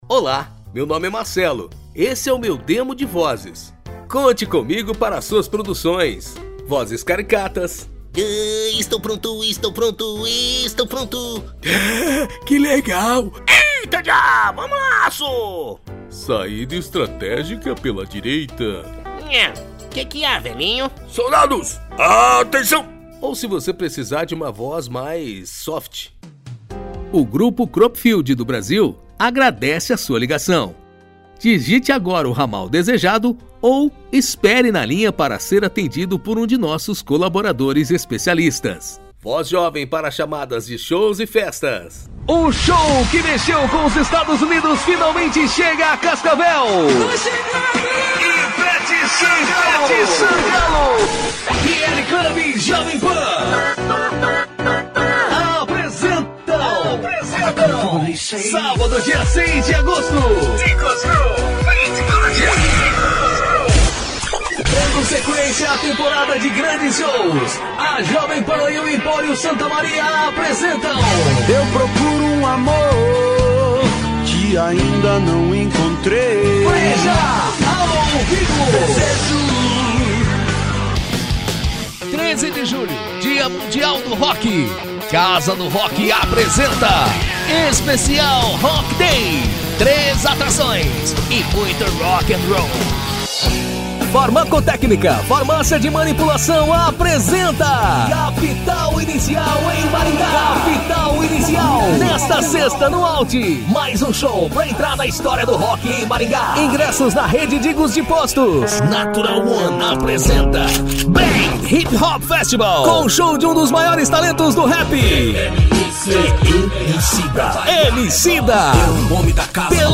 Spot Comercial
Vinhetas
Impacto
Animada
Caricata